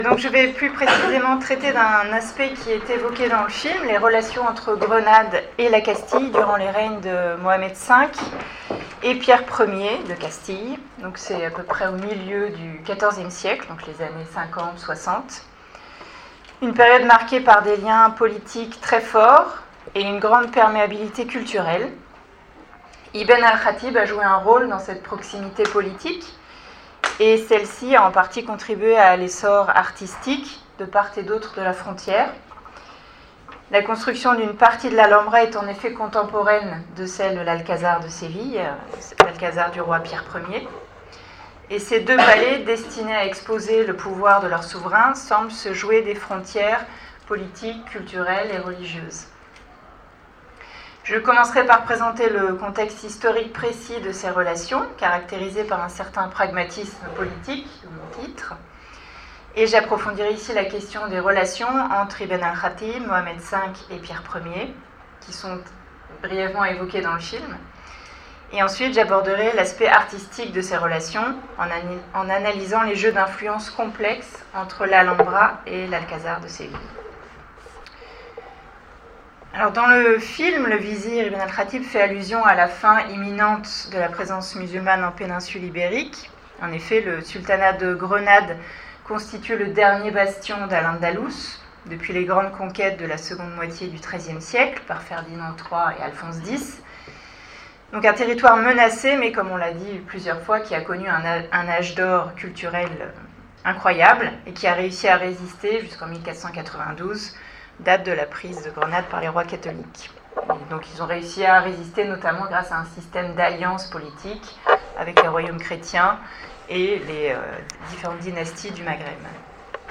Podcast d'une conférence sur les relations entre le royaume de Castille et le sultanat de Grenade au XIVème siècle. Ces relations, caractérisées de plus en plus par le pragmatisme politique, ont été propices à une grande émulation artistique qui a permis la construction de palais extraordinaires.